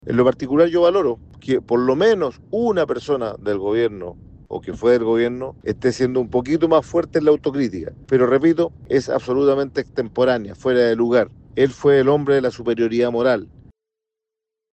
Una postura que generó algunos cuestionamientos en el Socialismo Democrático, desde donde el senador Fidel Espinoza (PS) afirmó que si bien “la autocrítica de Giorgio Jackson es positiva”, a su juicio es extemporánea.
511-cu-oficialismo-mat-fidel-espinoza.mp3